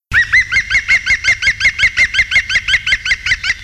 Pic vert
Picus viridis
Le cri territorial du pivert est très dense et sonore. Il ressemble à des éclats de rire.
pic-vert.mp3